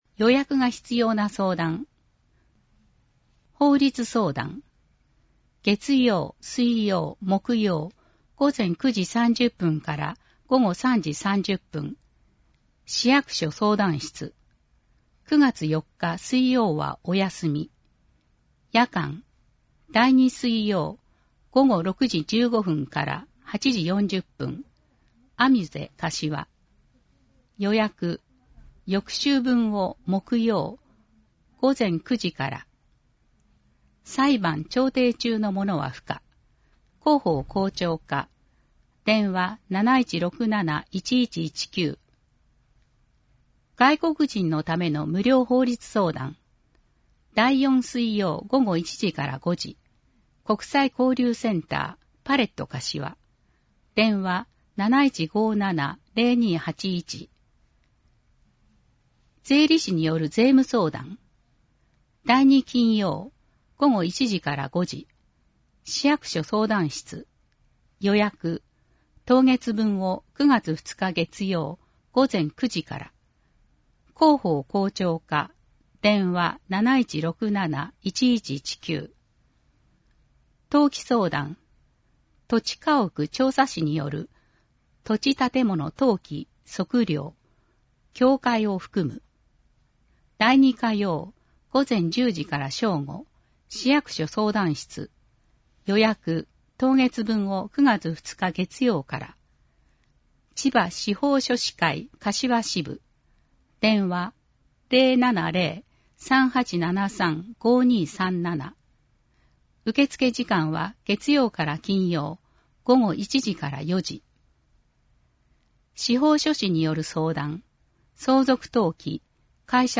• 広報かしわの内容を音声で収録した「広報かしわ音訳版」を発行しています。
• 発行は、柏市朗読奉仕サークルにご協力いただき、毎号行っています。